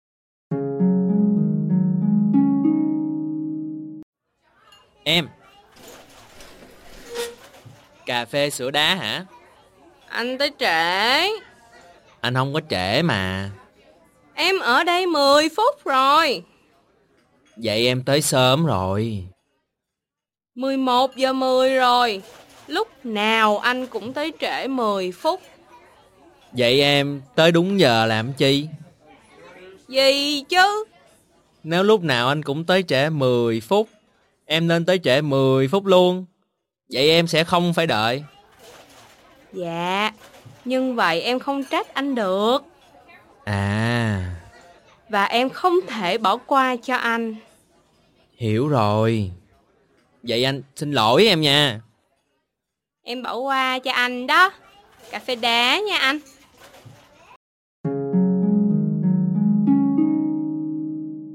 SAIGONESE
E012_dialogue.mp3